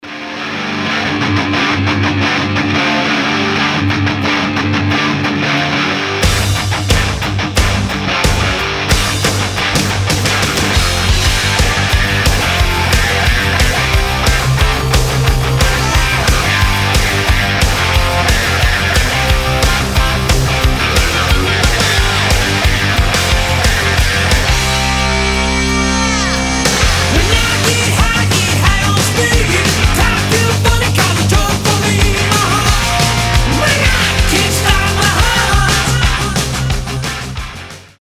• Качество: 320, Stereo
громкие
Драйвовые
Hard rock
80-е
heavy Metal
Glam Metal